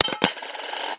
硬币掉落到锡中 " 硬币掉落3
描述：单枚硬币掉进一个罐子里
Tag: 硬币 镀锡 无编辑